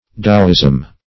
Taoism \Ta"o*ism\, n.